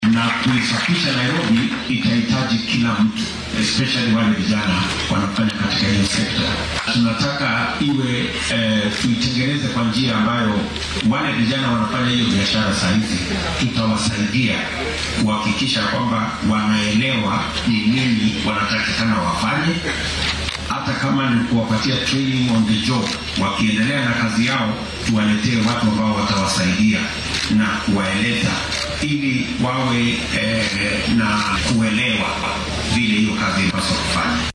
Madaxweyne ku xigeenka dalka William Ruto oo hooygiisa xaafadda Karen ee magaalada Nairobi kulan kula qaatay ururrada bulshada ayaa sheegay in caqabadda ugu